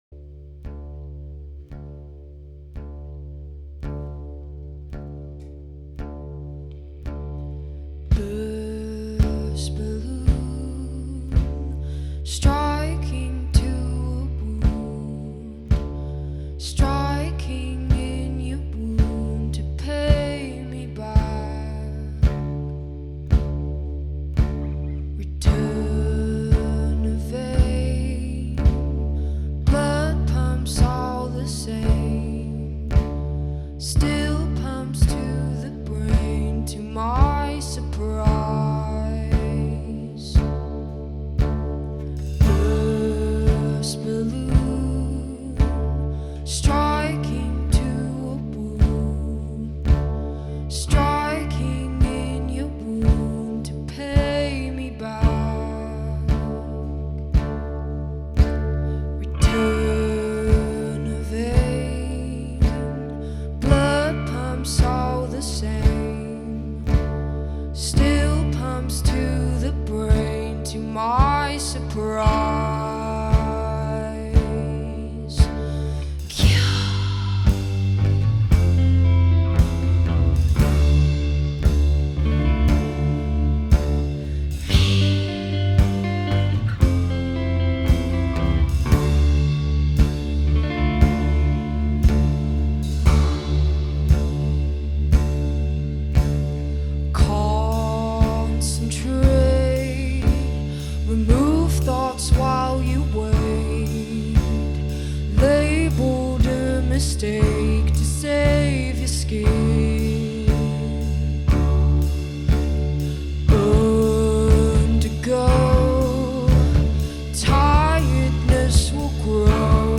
recorded at Low Four Studio
a mesmerizing sound
lead singer
intoxicatingly hypnotic voice
poised melancholic sound